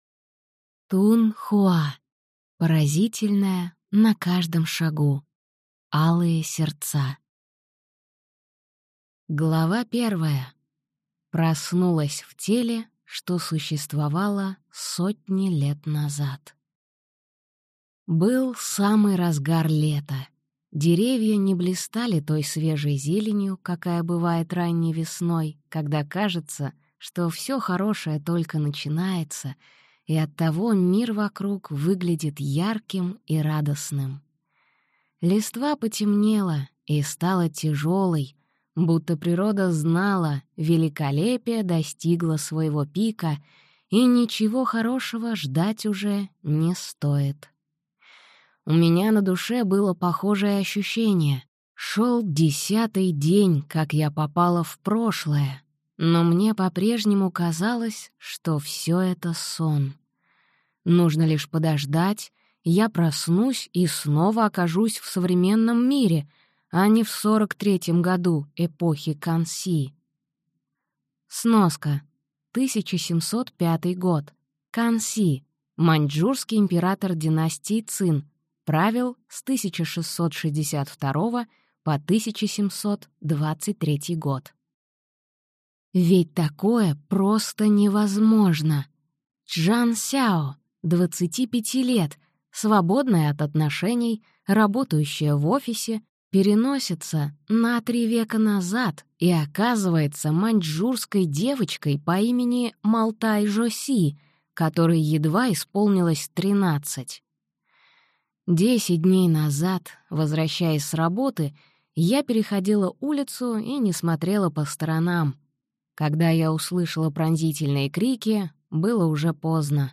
Аудиокнига Поразительное на каждом шагу. Алые сердца | Библиотека аудиокниг